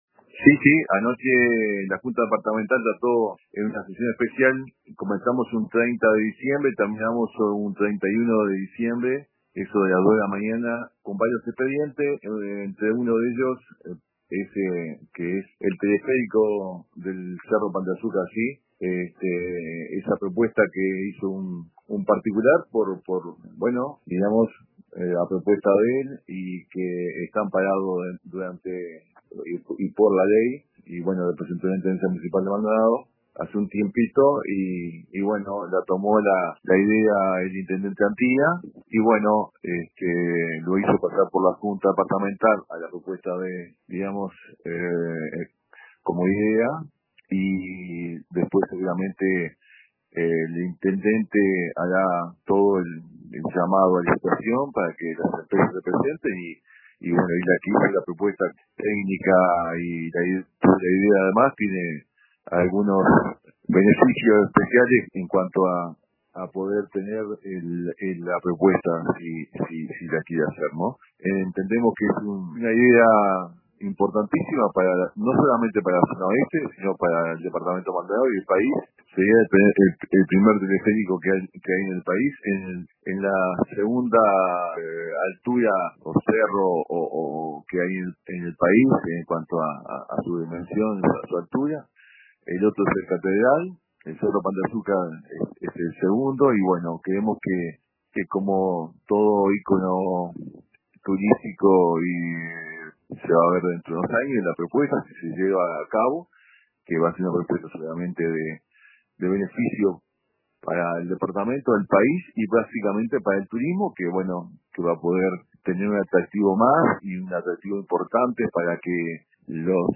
El edil departamental del Partido Nacional, Javier Sena, expresó en el programa Radio Con Todos de RBC: